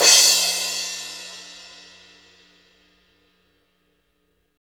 Index of /90_sSampleCDs/Roland L-CDX-01/CYM_Crashes 1/CYM_Crsh Modules
CYM 15 DRK09.wav